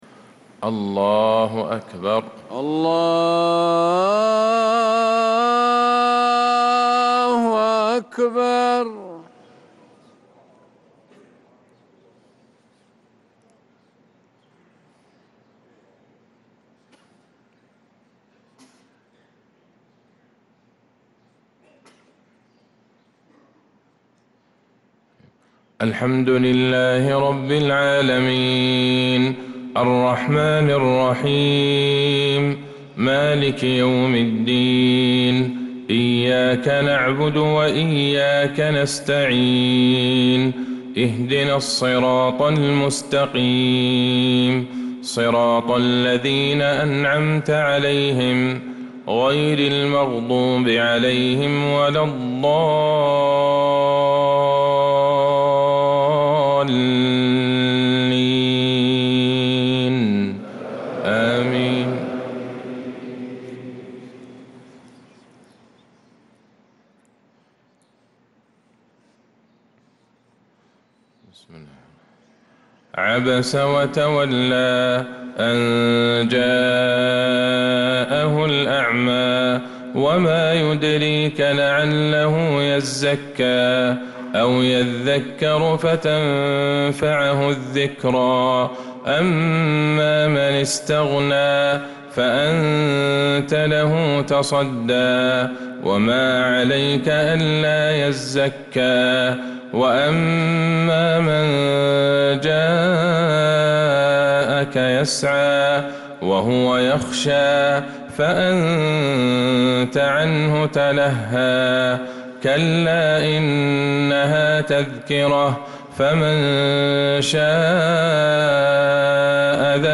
فجر الأربعاء ١١ ربيع الأول ١٤٤٧ سورتي عبس والطارق كاملة | Fajr prayer from surah Abasa and Al-Tariq 3-9-2025 > 1447 🕌 > الفروض - تلاوات الحرمين